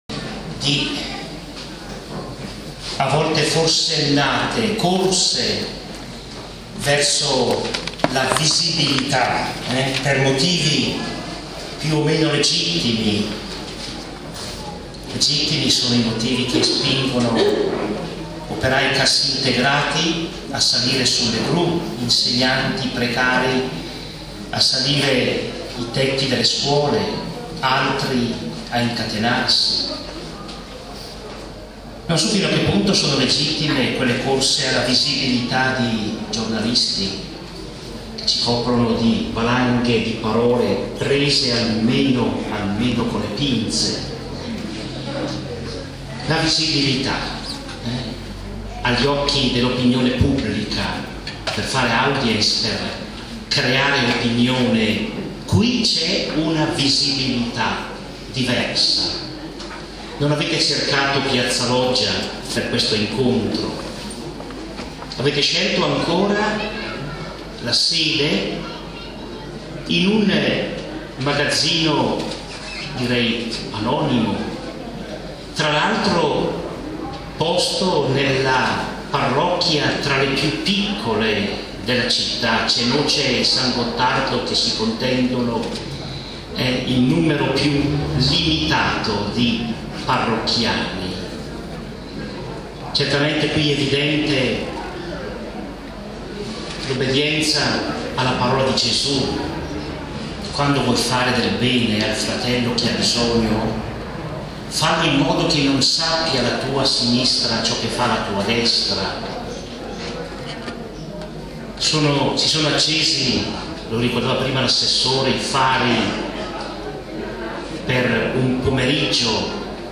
Inaugurazione dell'anno sociale 2009-2010
Nel suo saluto (per ascoltarlo